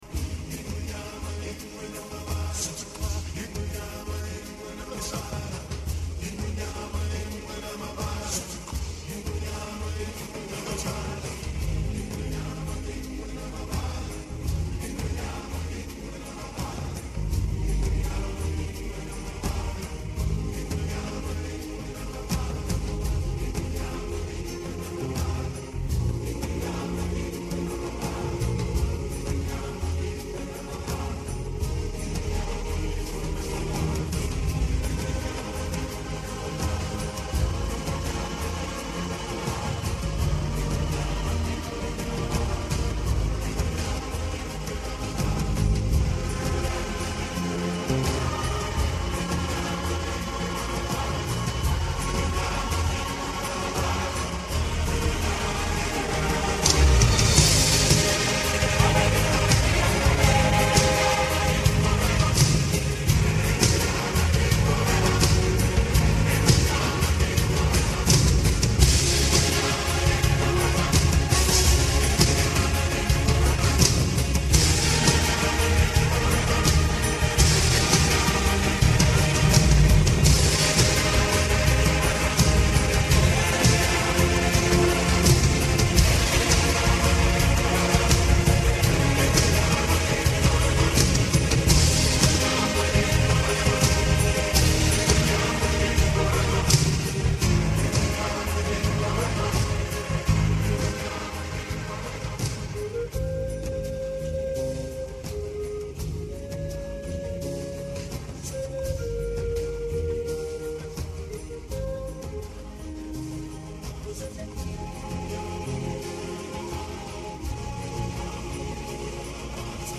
TROMBONE SOLO
TROMBONE SOLO • ACCOMPAGNAMENTO BASE MP3